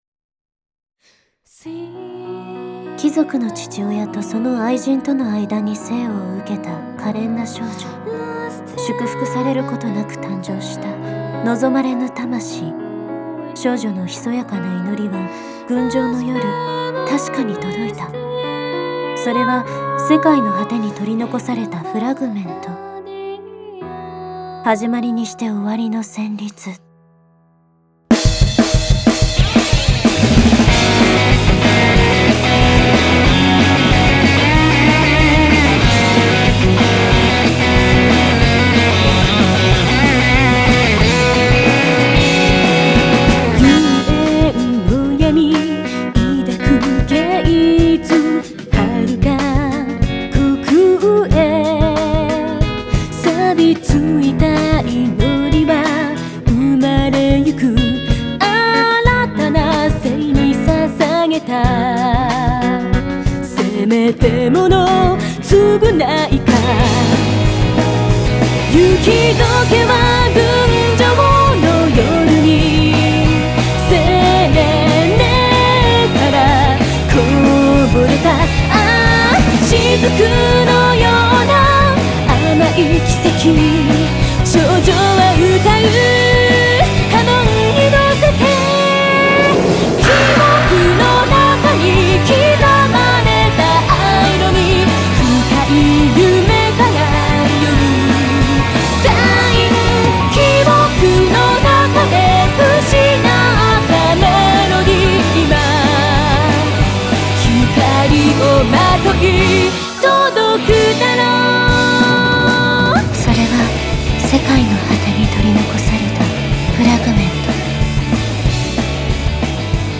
※この試聴ファイルはプリマスタリング前のデータです。